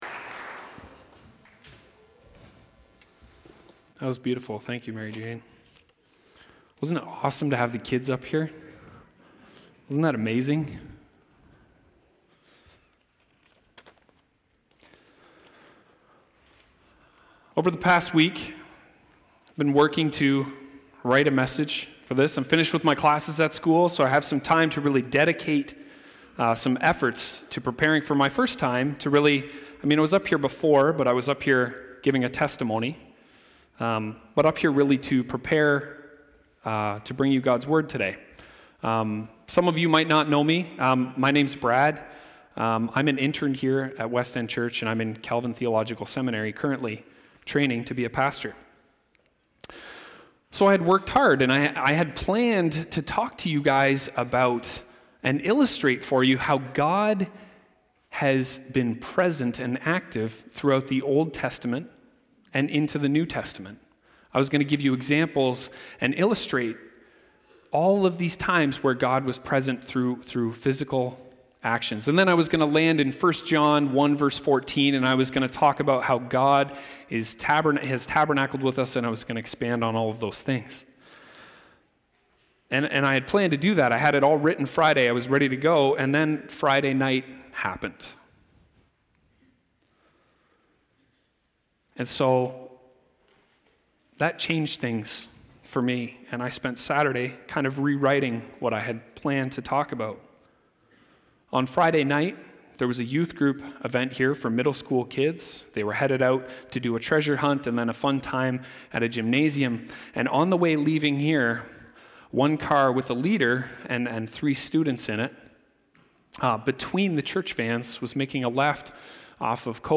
Preacher
December 15, 2019 (Morning Worship)